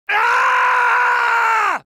Werehog Fall Scream